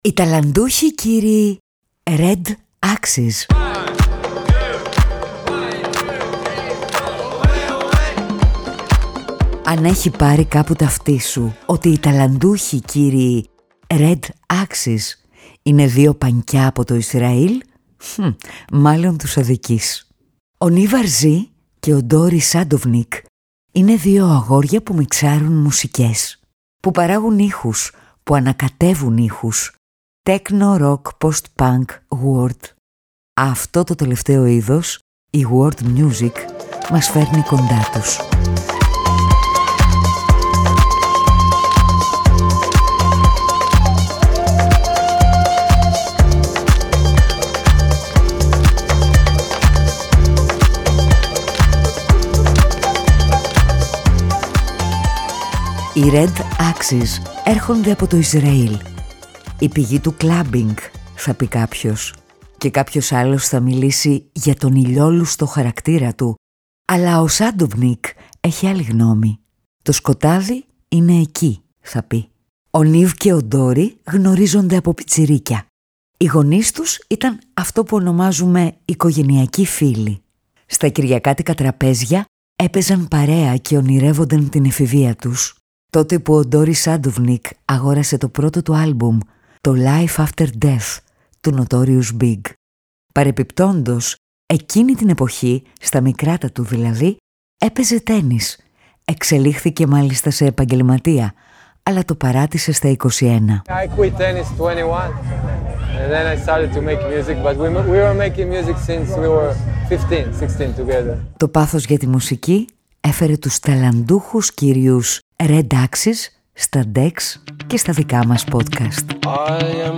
Αυτή τη βδομάδα, καλεσμένοι σ’ αυτό το podcast είναι οι ταλαντούχοι κύριοι…. Red Axes. Δύο αγόρια από το Ισραήλ που αγαπούν να ταξιδεύουν σ’ όλο τον κόσμο, να ηχογραφούν τοπικές μουσικές, να δημιουργούν electro beats και να ρεμιξάρουν.